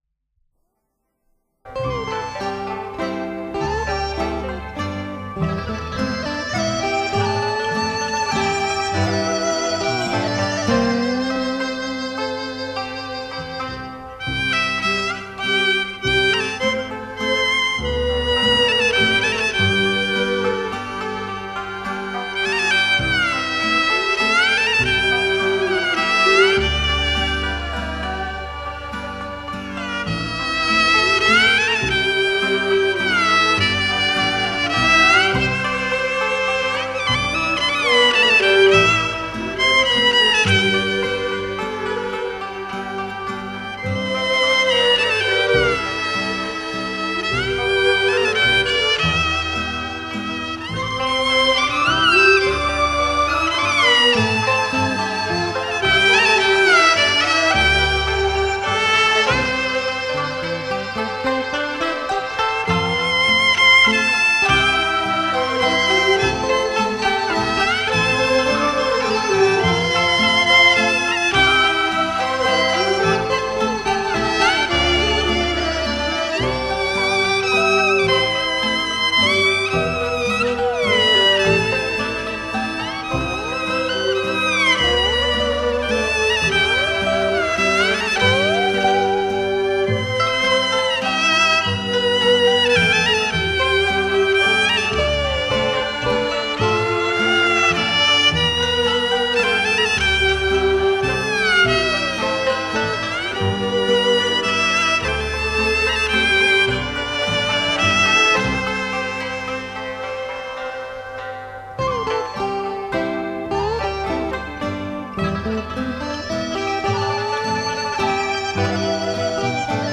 音色纯朴明亮，通透甘美
充满了浓郁的广东地方色彩